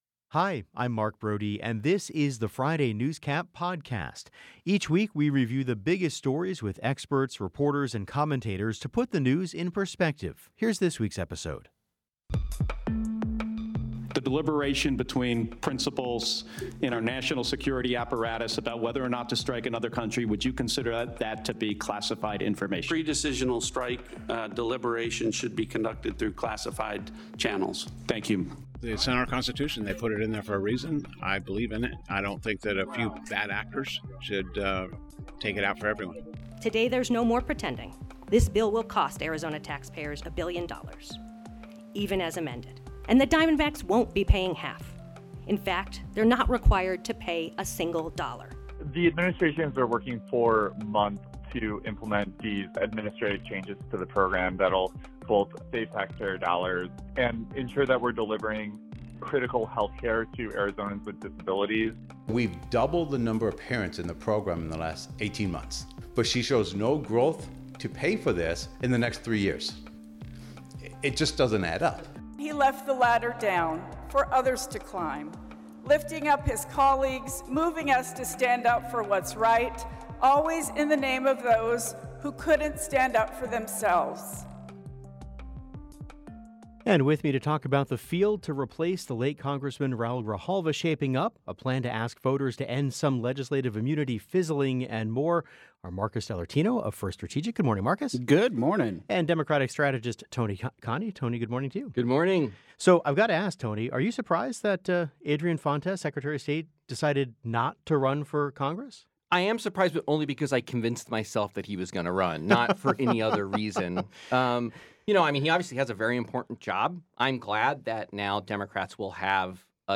The Show's Friday Newscap is a weekly review of the biggest stories with experts, reporters and commentators to put the news in perspective.